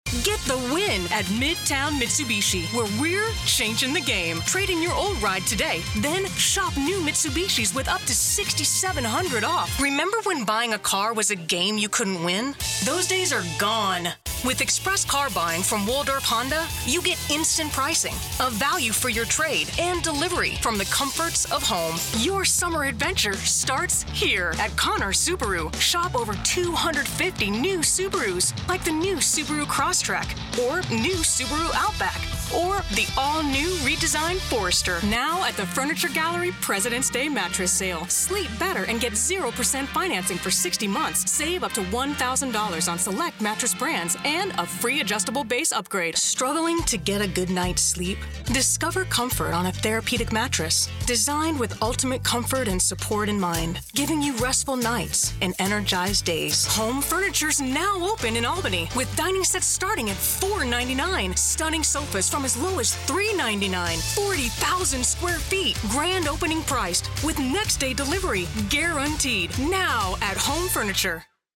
Female Voiceover
Catapult your sales with a high-energy, persuasive, and trustyworthy voice to convey your key selling points with a conversational yet compelling call to action.